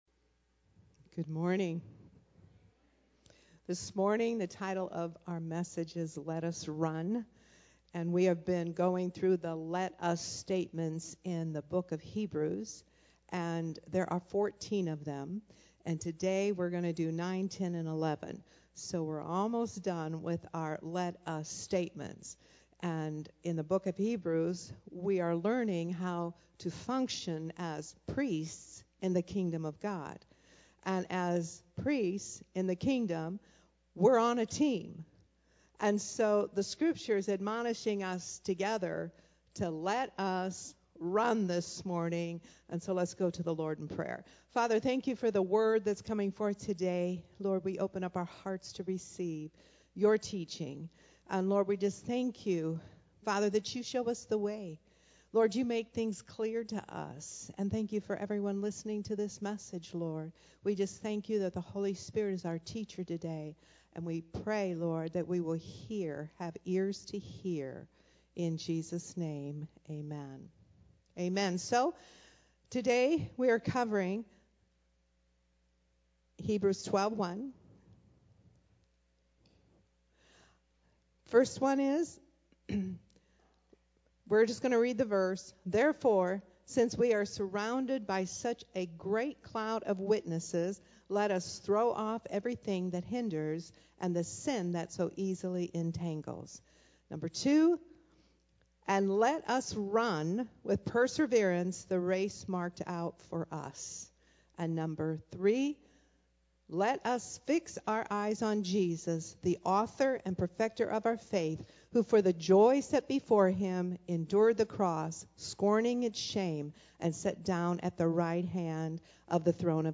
Sermons Archive - Praysers Ministries, Inc.